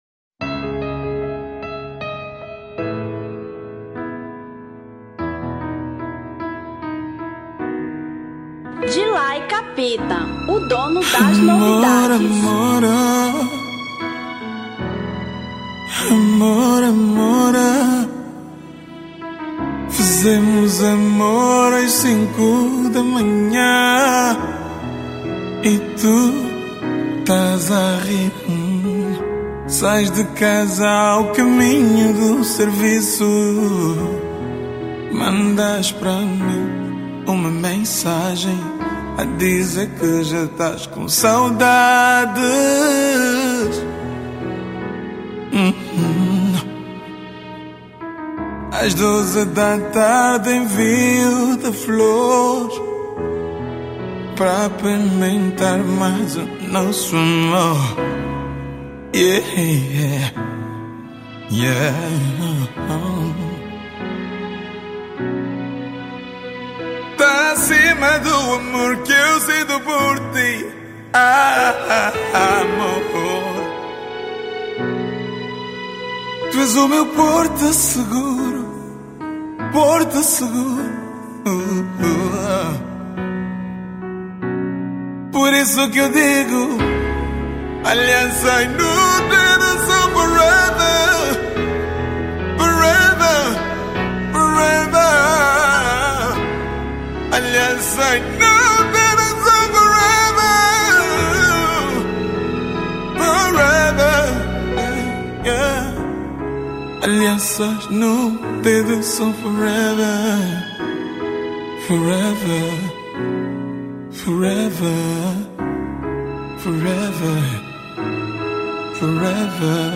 Kizomba 2017